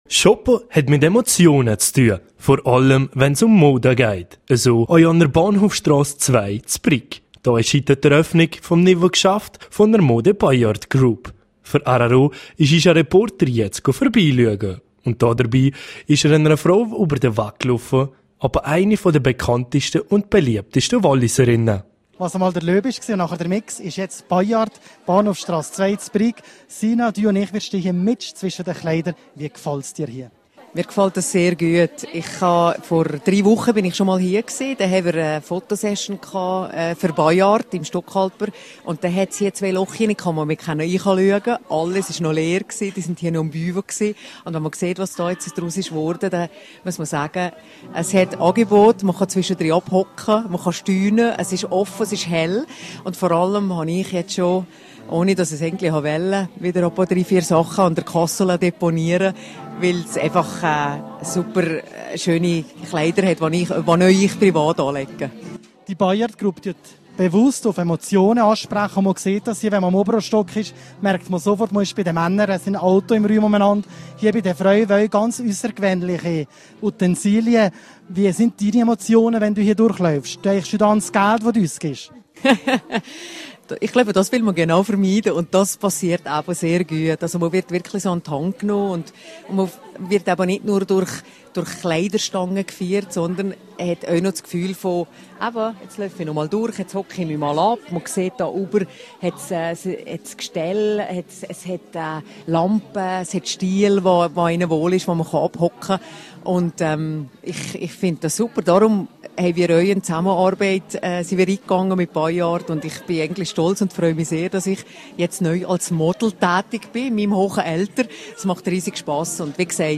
14210_News.mp3